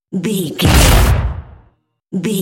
Dramatic hit explosion electricity
Sound Effects
heavy
intense
dark
aggressive